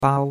bao1.mp3